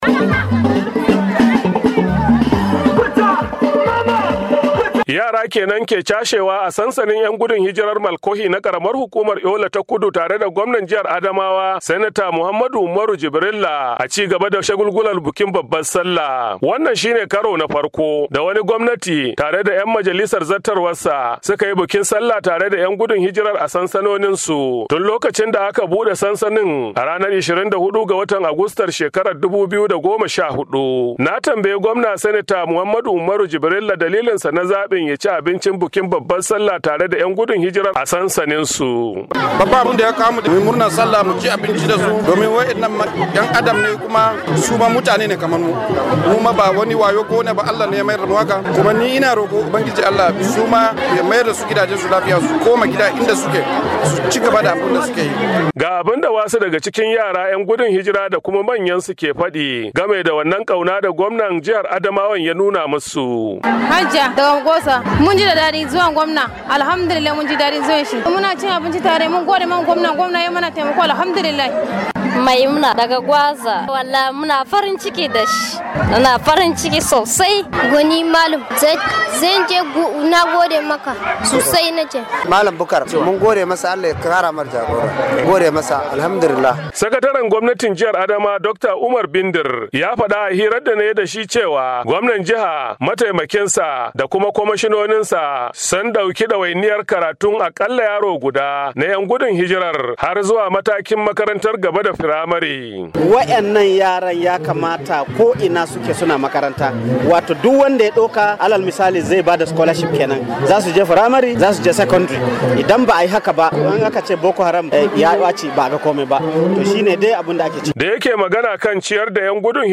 Sakataren gwamnatin jiha Dr. Umar Bindir ya furta haka a hirarsa da Muryar Amurka lokacin walimar cin abincin bukin babbar Sallah da gwamnan jihar Adamawa Sanata Muhammadu Umaru Jibirilla ya jagoranta a sansanin Malkohi na karamar hukumar Yola ta Kudu.